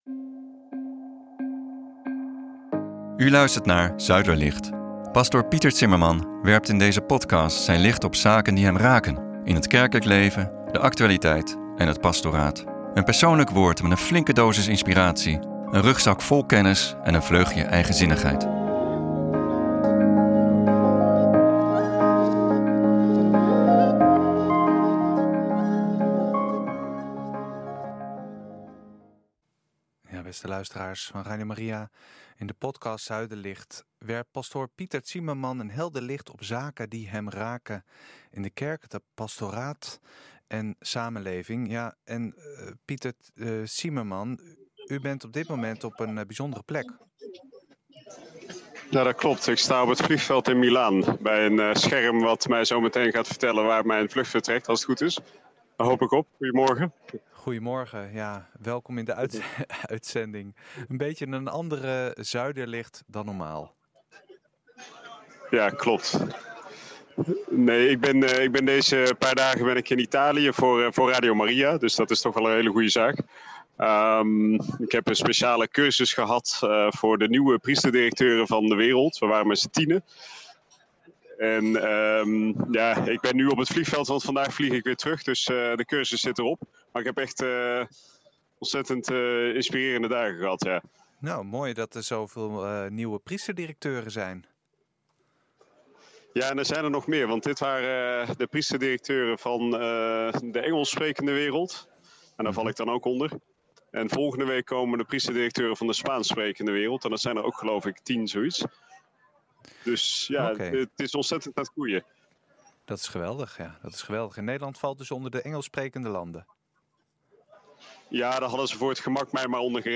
Zuiderlicht vanaf de luchthaven
vanaf de luchthaven van Milaan.